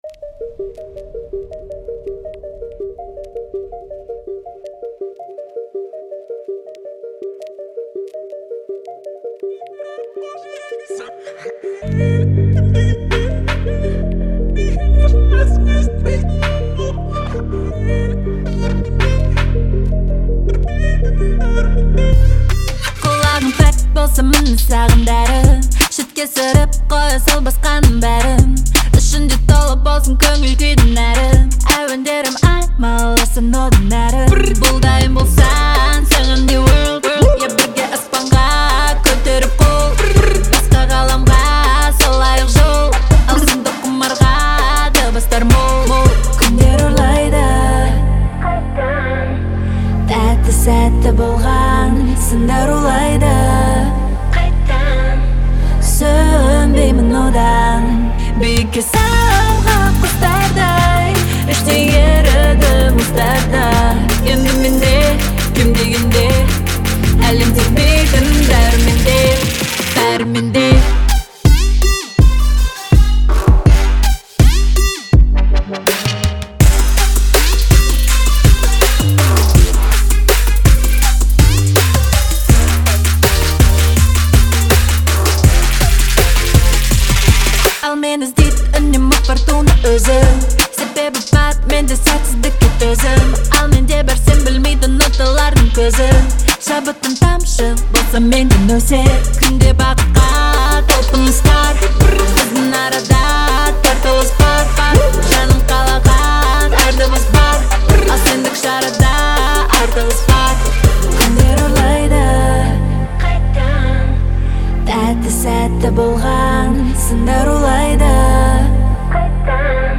это трек в жанре поп с элементами электроники